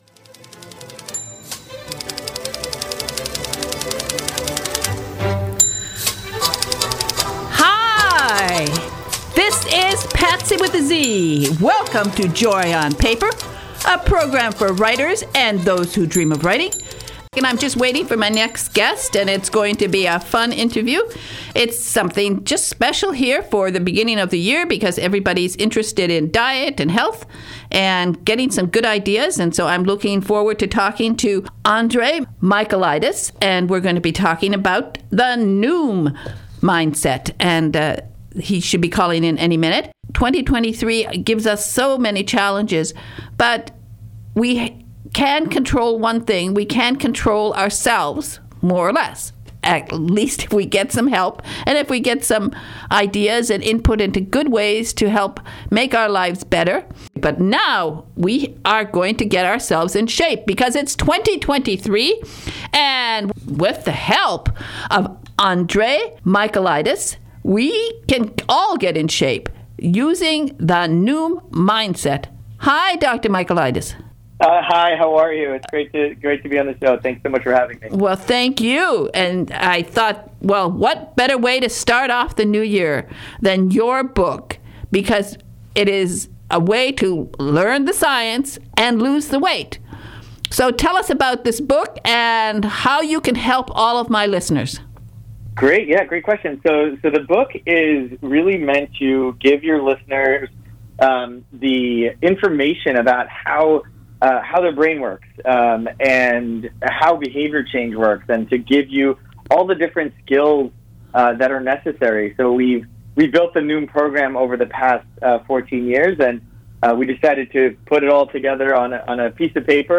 This is the first interview today